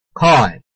拼音查詢：【饒平腔】koi ~請點選不同聲調拼音聽聽看!(例字漢字部分屬參考性質)